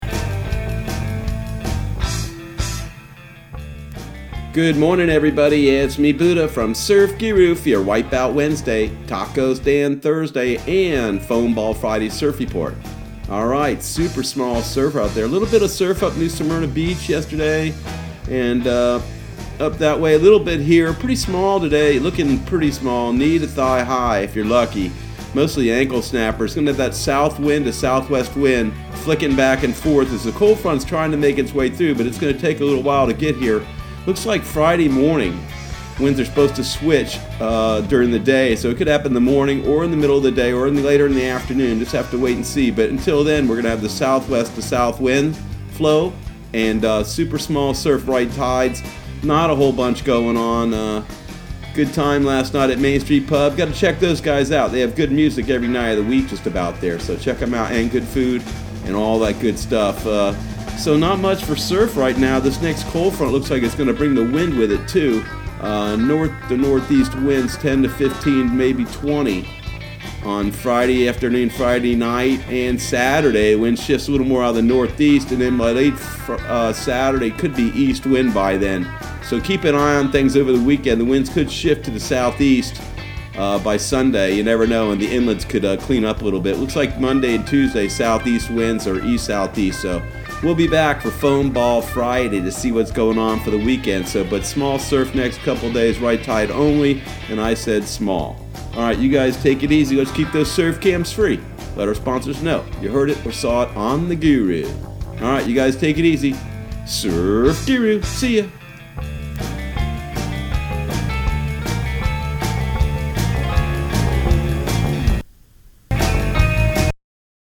Surf Guru Surf Report and Forecast 03/04/2020 Audio surf report and surf forecast on March 04 for Central Florida and the Southeast.